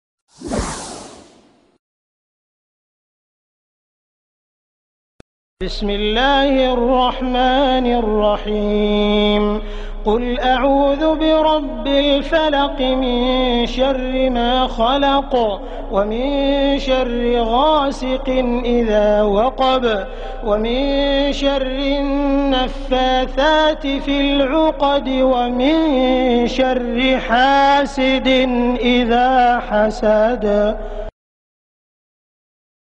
Soothing recitation of Surah al-Falaq by Sheikh Sudais. Masha Allah.